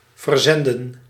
Ääntäminen
IPA: /vərˈzɛndə(n)/